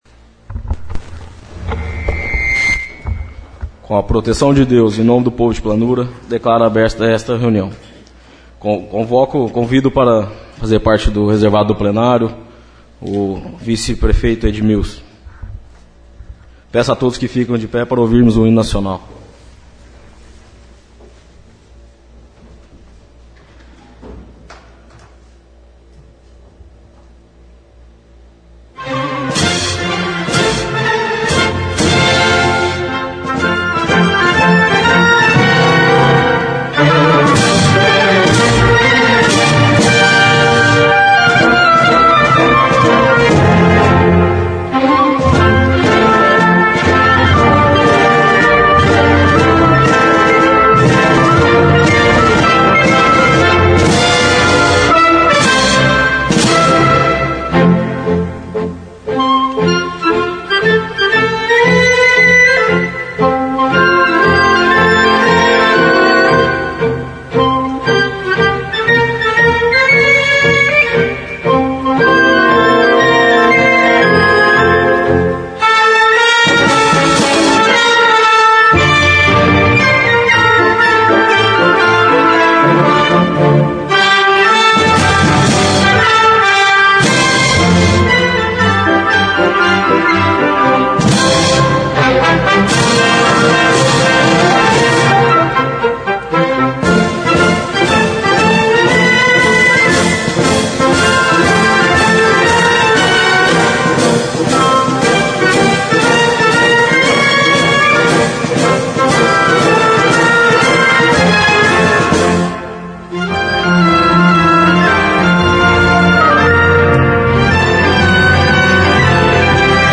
Sessão Ordinária - 21/09/15